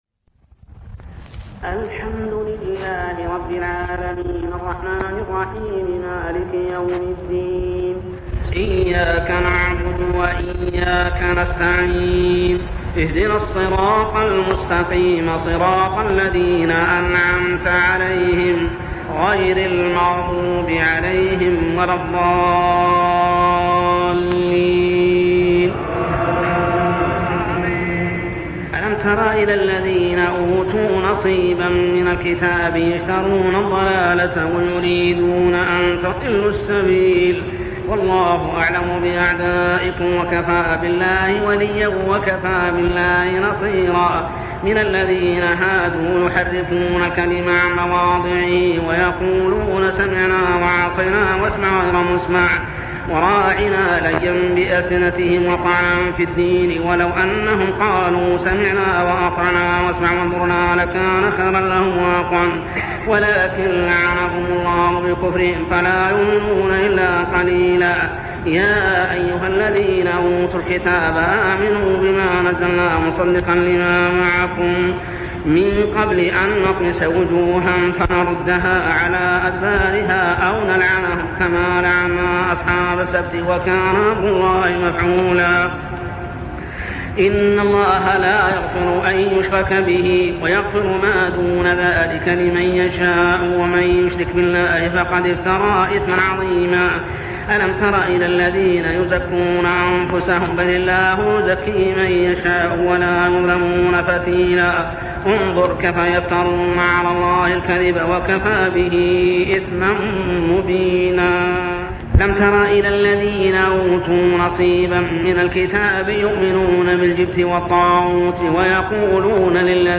صلاة التراويح عام 1401هـ سورة النساء 44-86 | Tarawih prayer Surah An-nisa > تراويح الحرم المكي عام 1401 🕋 > التراويح - تلاوات الحرمين